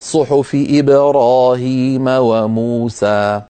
﴾صُحُفِ إِبۡرَٰهِيمَ وَمُوسَـىٰ ١٩﴿             förlängs förlängs (2) vokallängder, uttalas på följande sätt